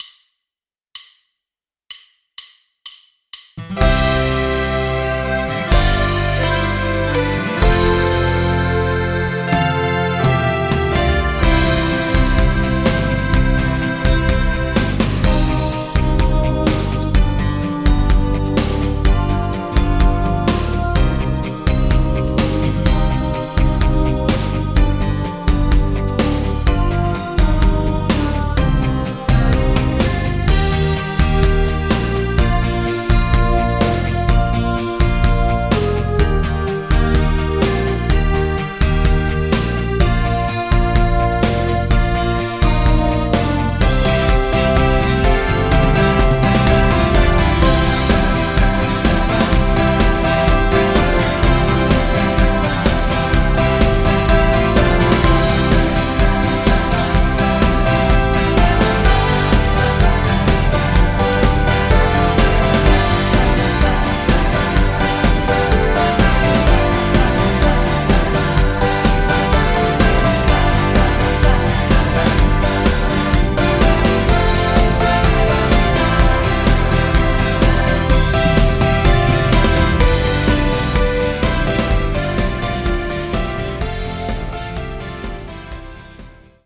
Playback, Karaoke, Instrumental